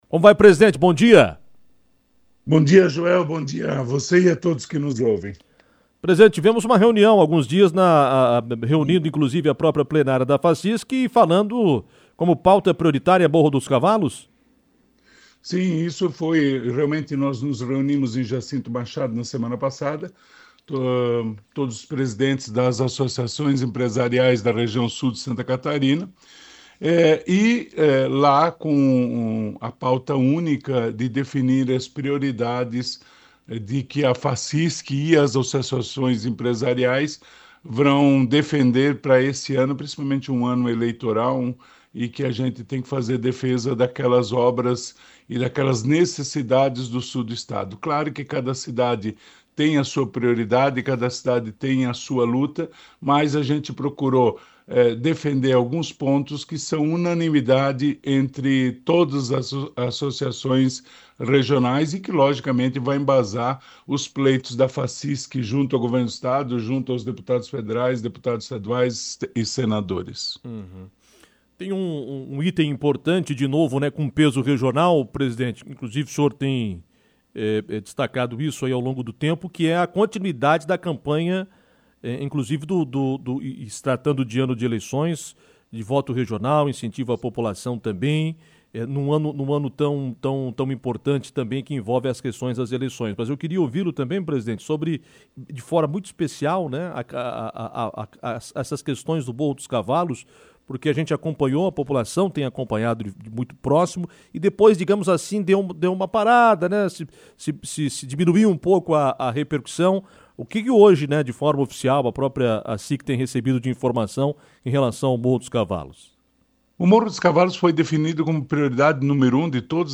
destacou sobre a demanda em entrevista